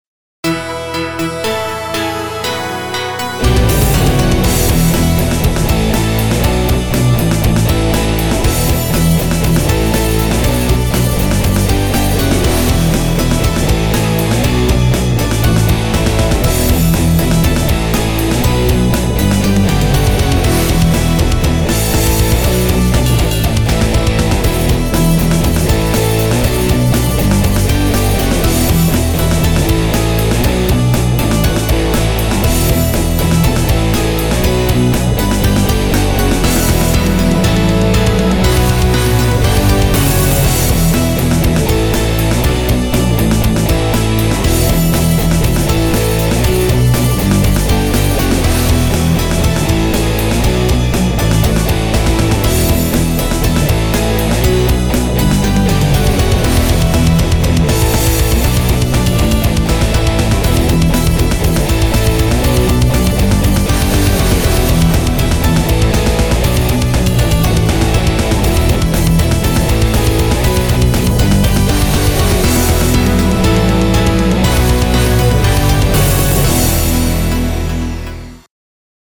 музыка из заставки (в рок-обработке)